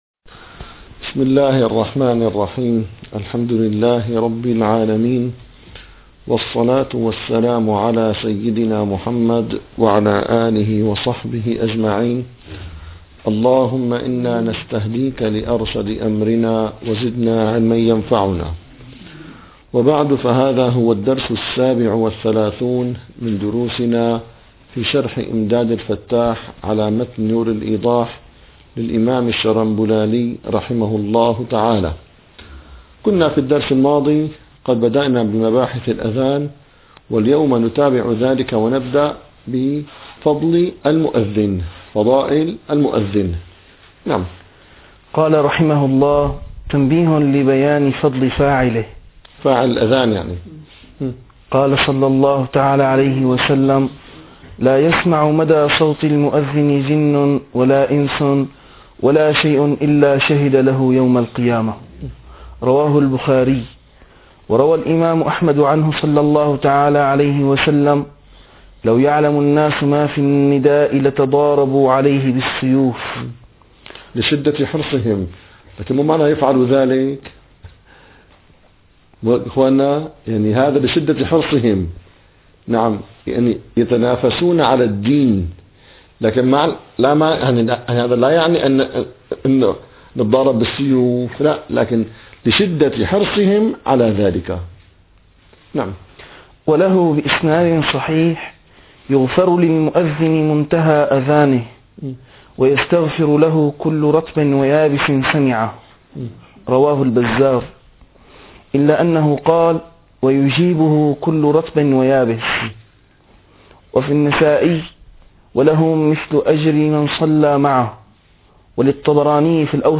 - الدروس العلمية - الفقه الحنفي - إمداد الفتاح شرح نور الإيضاح - 37- تنبيه لبيان فضل فاعله